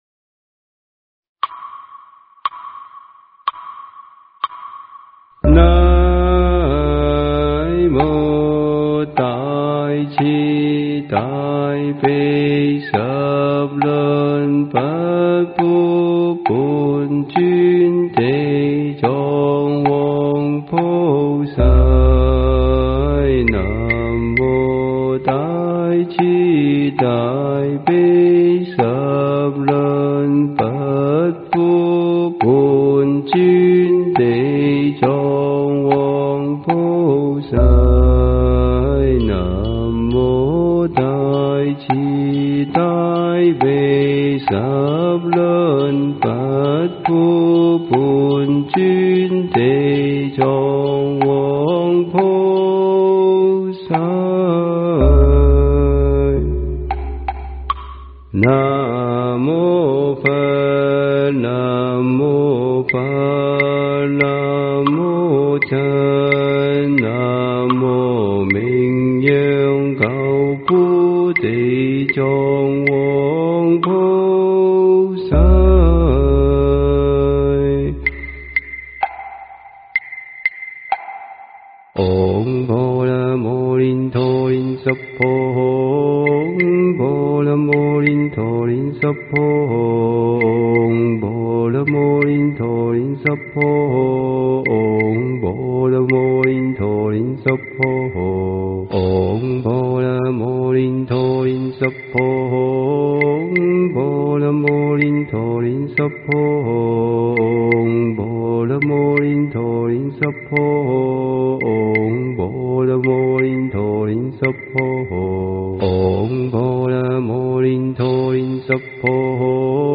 佛音 经忏 佛教音乐 返回列表 上一篇： 楞严神咒首部曲--毗卢真法会 下一篇： 往生咒--五明佛学院僧众 相关文章 八十八佛忏（早课）--普寿寺尼众 八十八佛忏（早课）--普寿寺尼众...